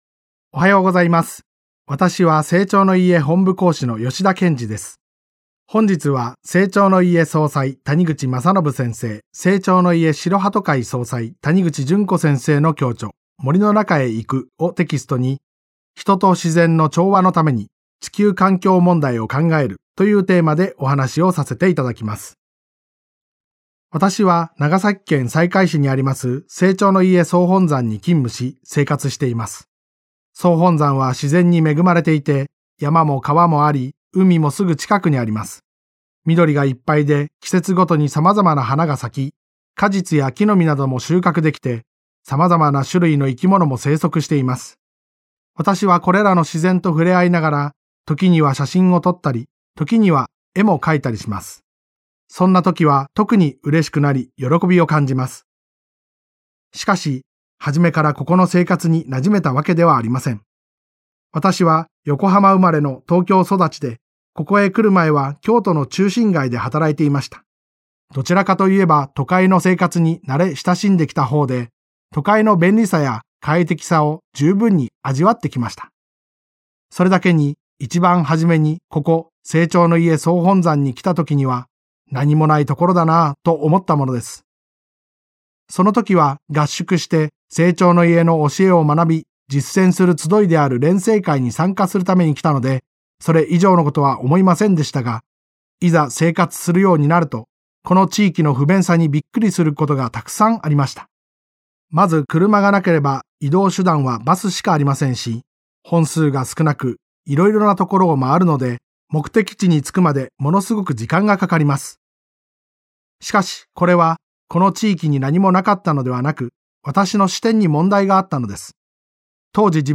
生長の家がお届けするラジオ番組。